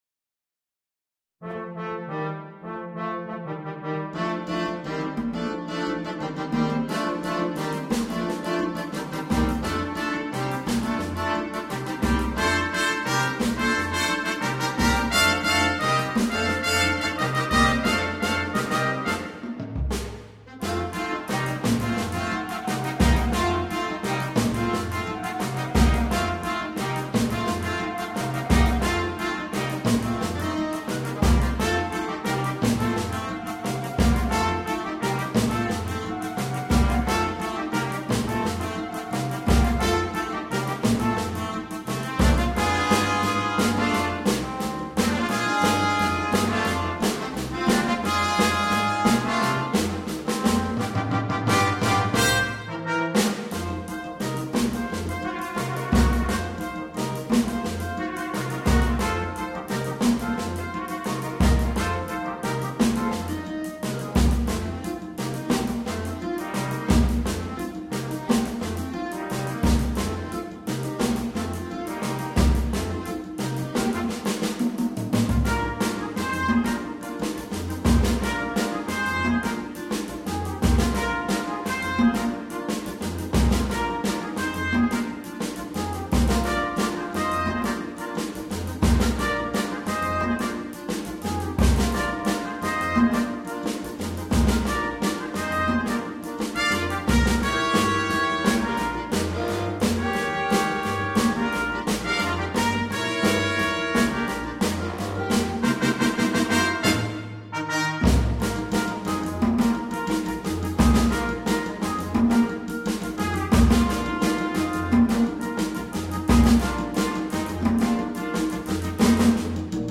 на смешанный состав